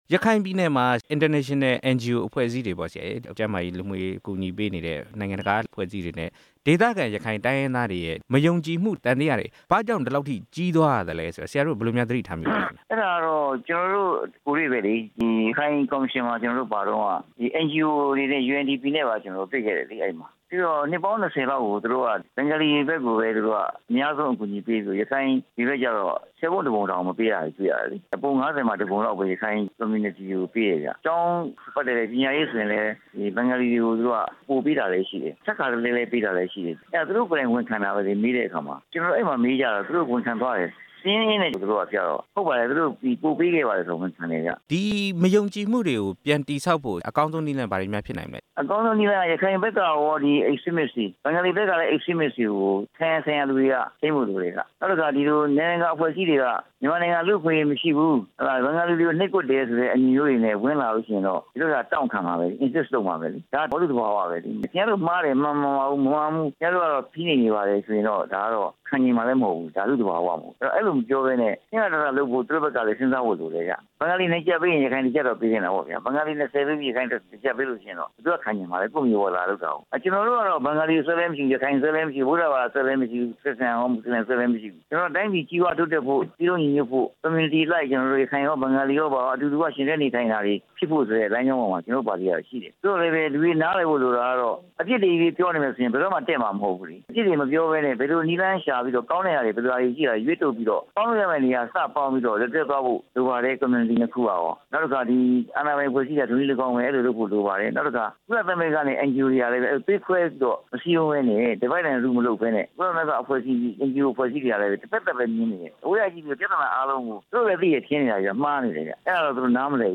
NDF အကြီးအကဲ ဦးခင်မောင်ဆွေနဲ့ မေးမြန်းချက်